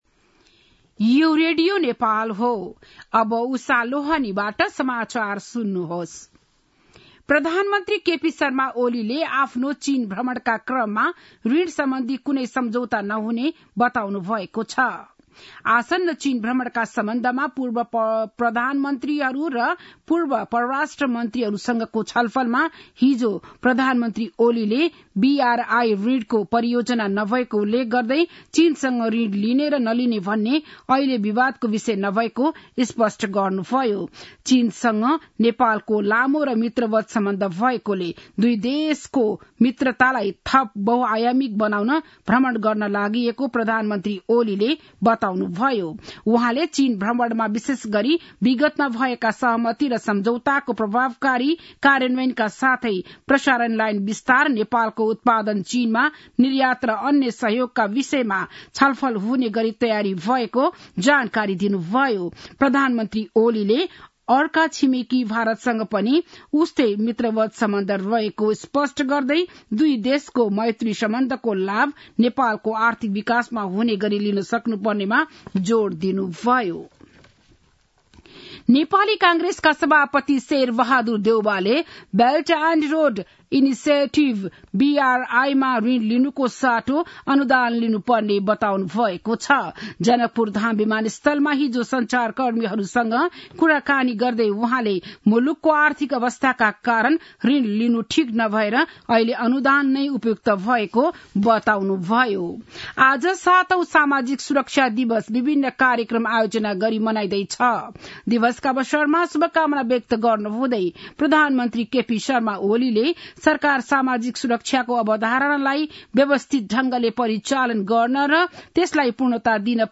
An online outlet of Nepal's national radio broadcaster
बिहान ११ बजेको नेपाली समाचार : १२ मंसिर , २०८१
11-am-nepali-news-1-9.mp3